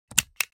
دانلود صدای کلیک 24 از ساعد نیوز با لینک مستقیم و کیفیت بالا
جلوه های صوتی
برچسب: دانلود آهنگ های افکت صوتی اشیاء دانلود آلبوم صدای کلیک از افکت صوتی اشیاء